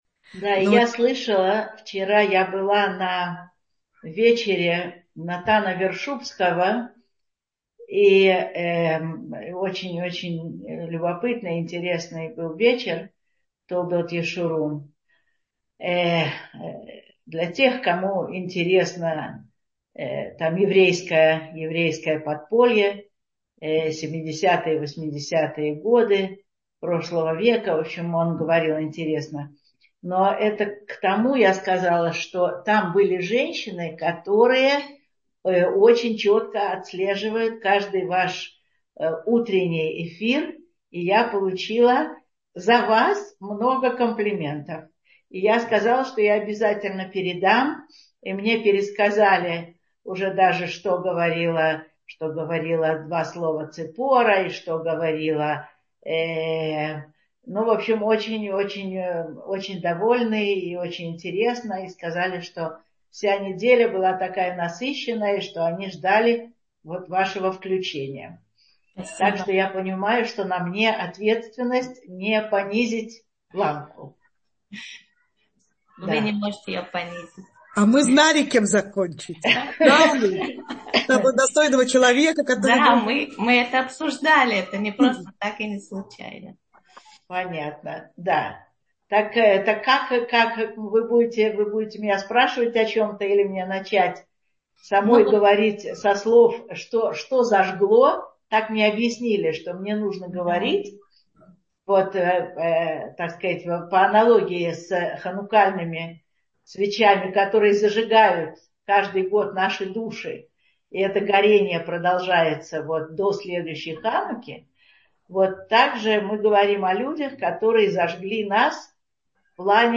Утреннее Zoom ток-шоу «Утро с Толдот» приглашает вас на наш традиционный ханукальный Зум-марафон с кратким «спринт»-включением наших лекторов каждый день праздника.